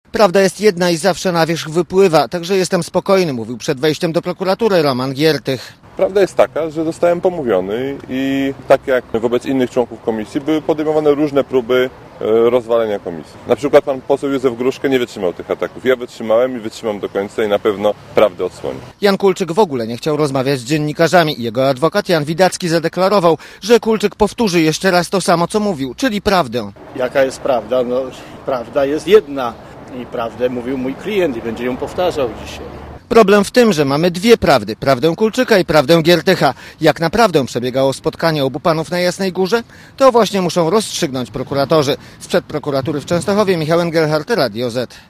reportera Radia ZET